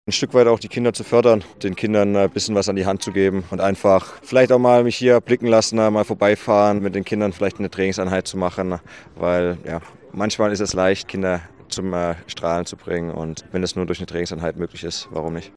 OT Grischa Prömel Anpfiff ins Leben